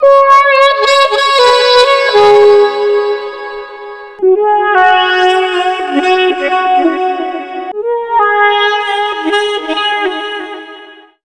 Index of /90_sSampleCDs/Zero-G - Total Drum Bass/Instruments - 3/track67 (Riffs Licks)
07-The Mute 170 bpm.wav